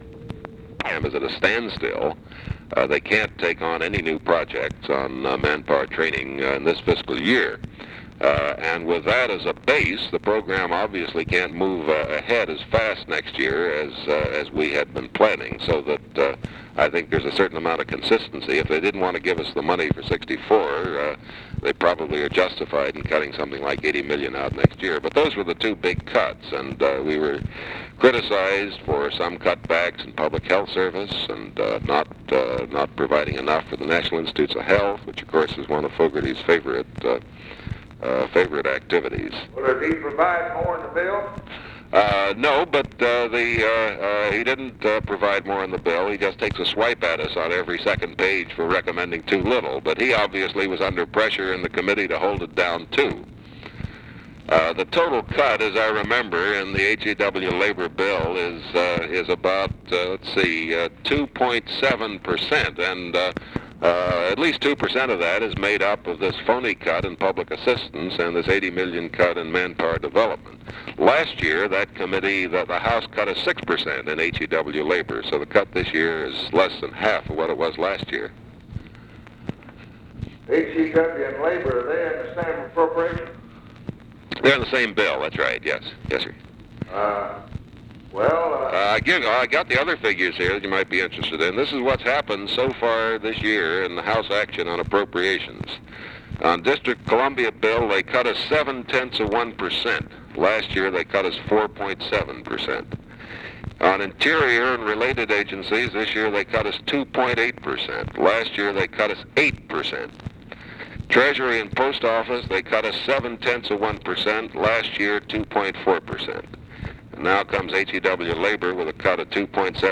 Conversation with KERMIT GORDON, April 11, 1964
Secret White House Tapes